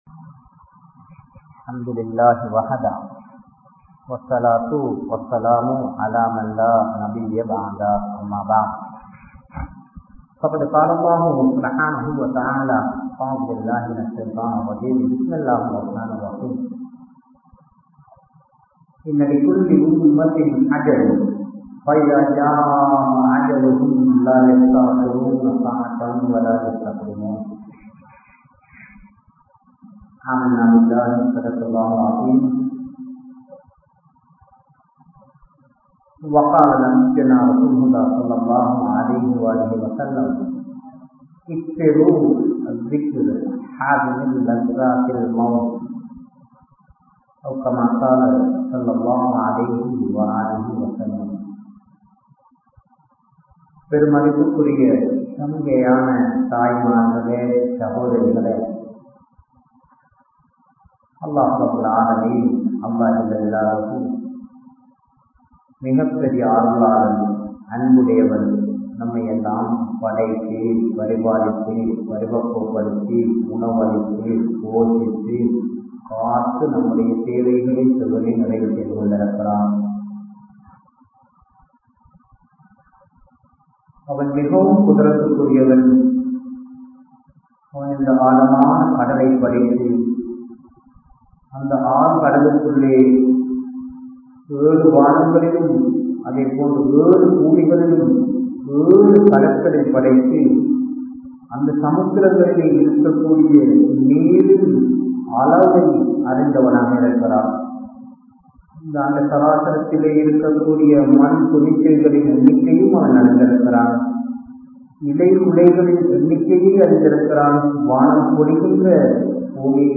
Maranikka Piranthavarhal (மரணிக்க பிறந்தவர்கள்) | Audio Bayans | All Ceylon Muslim Youth Community | Addalaichenai